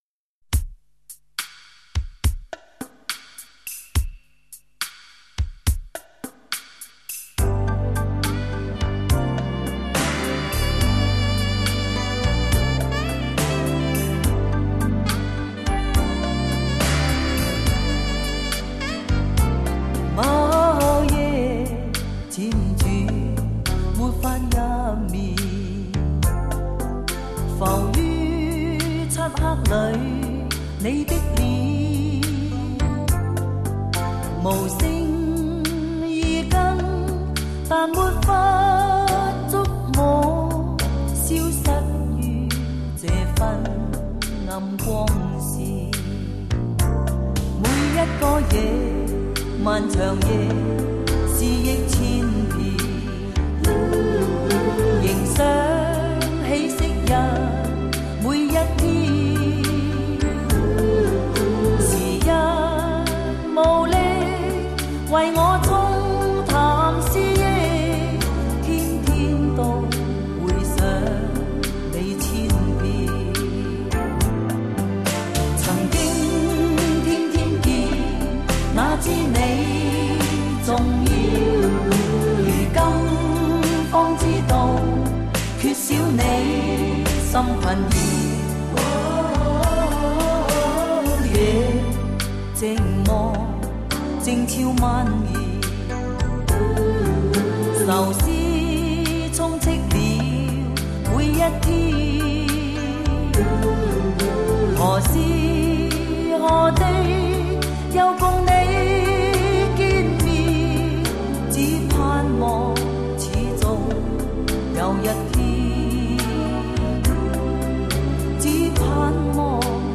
为那宽厚圆润的嗓音所倾倒！